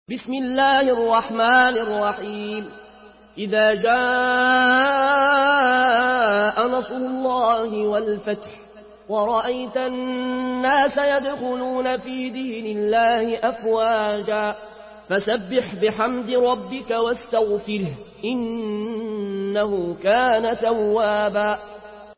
Surah An-Nasr MP3 by Al Ayoune Al Koshi in Warsh An Nafi From Al-Azraq way narration.
Murattal